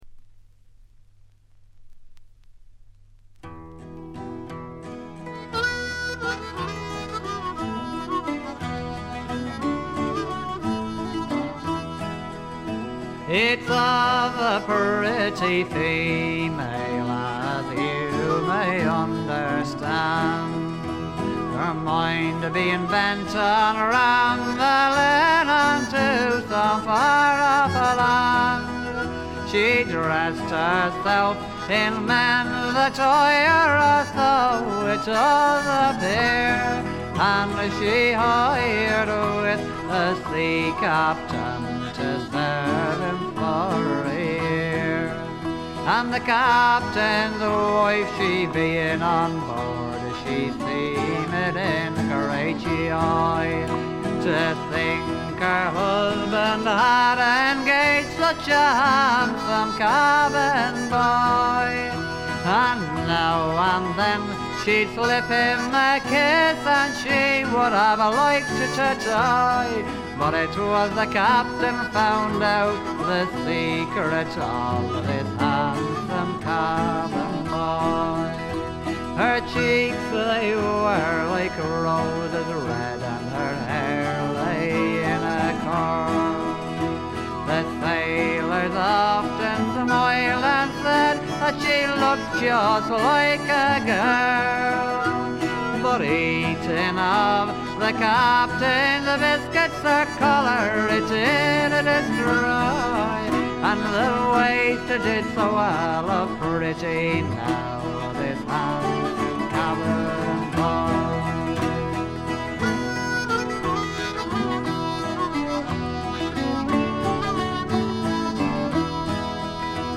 試聴曲は現品からの取り込み音源です。
Vocals, Bouzouki, Tin Whistle
Vocals, Guitar, Banjo, Concertina
Vocals, Mandolin, Harmonica, Guitar